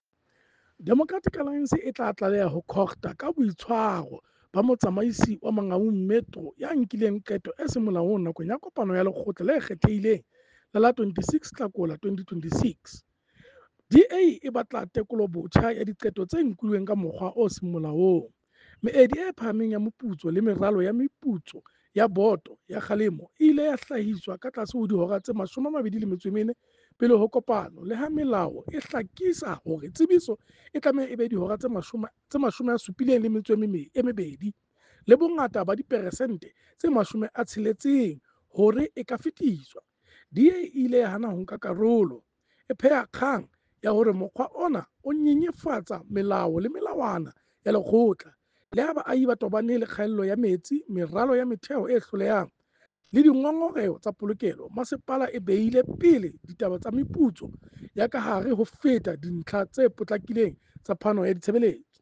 Sesotho soundbite by Cllr Kabelo Moreeng